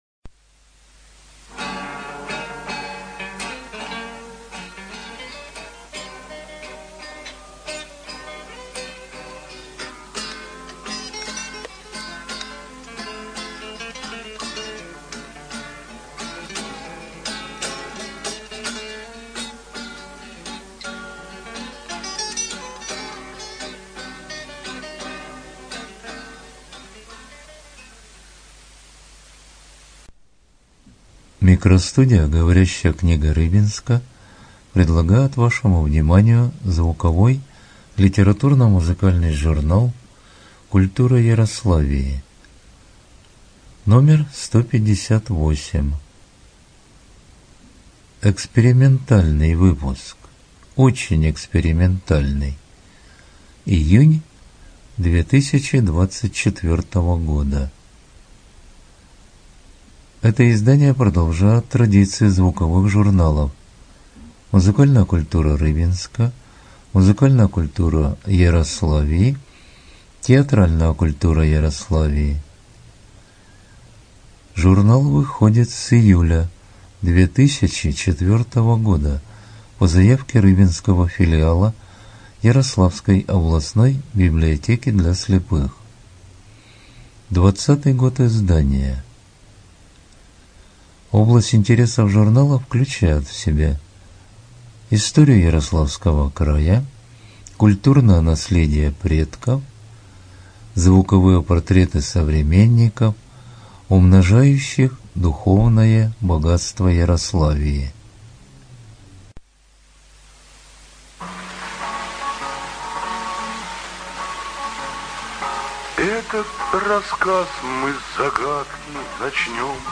Студия звукозаписиГоворящая книга Рыбинска